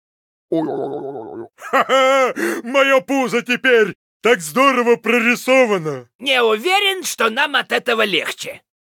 Вместо этого предлагаем ознакомиться с новыми звуковыми файлами из игрового клиента Heroes of the Storm, добавленными вместе с новым героем — Потерявшимися Викингами.
LostVikingsBase_Pissed06.ogg